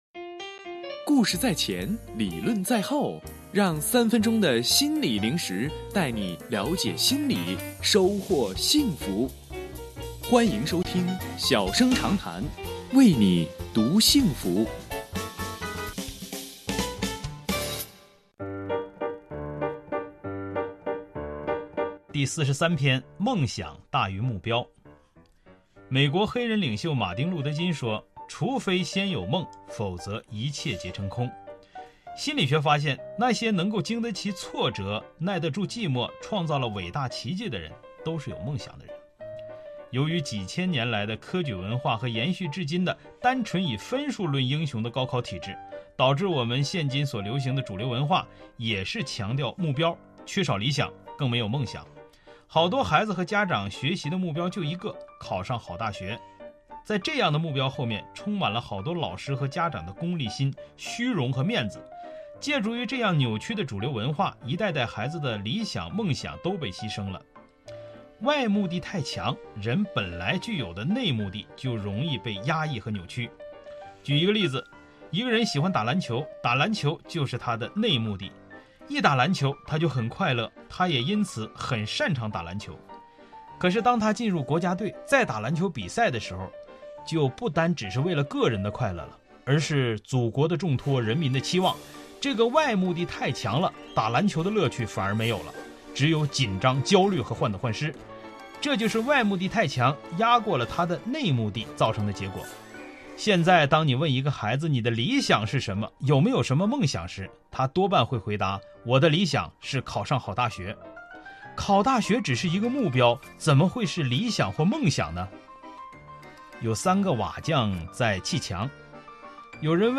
音频来源：吉林广播电视台 新闻综合广播